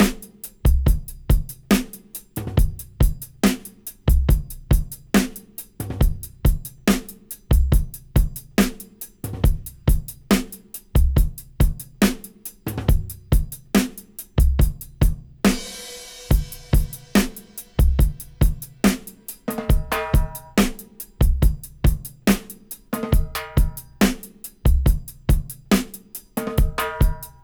70-DRY-03.wav